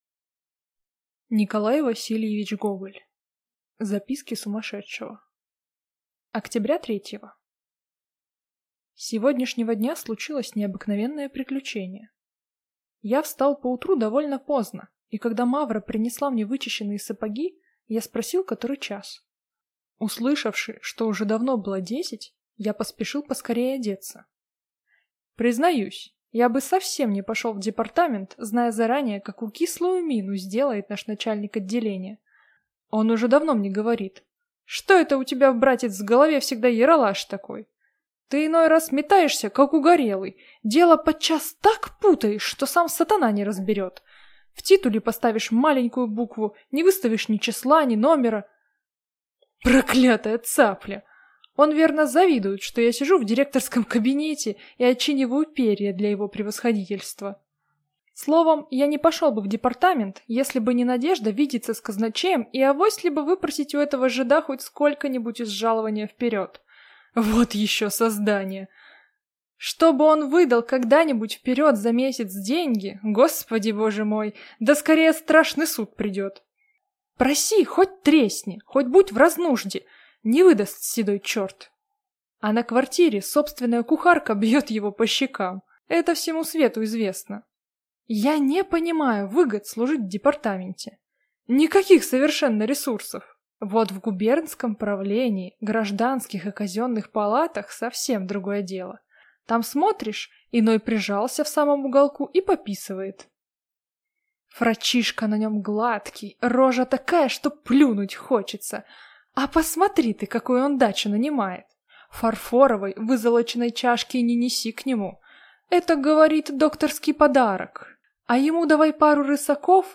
Аудиокнига Записки сумасшедшего | Библиотека аудиокниг